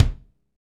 Index of /90_sSampleCDs/Northstar - Drumscapes Roland/KIK_Kicks/KIK_Funk Kicks x
KIK FNK K0FR.wav